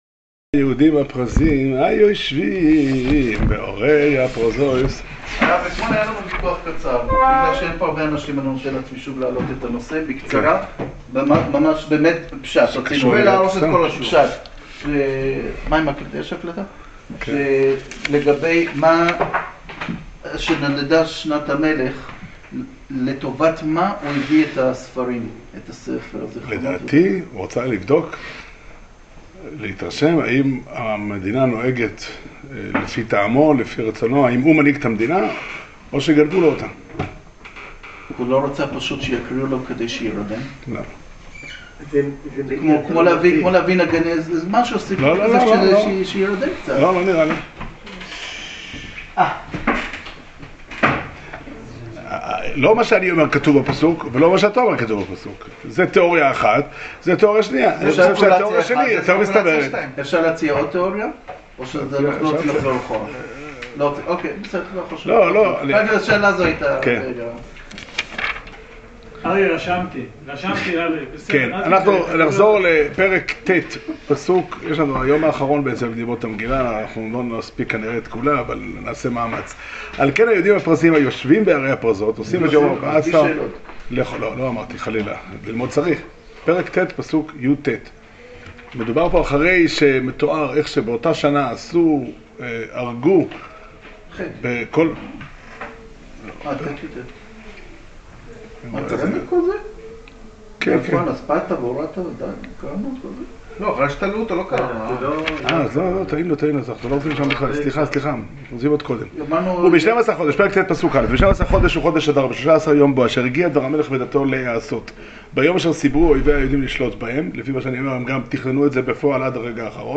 שיעור שנמסר בבית המדרש פתחי עולם בתאריך י"ג אדר ב' תשע"ט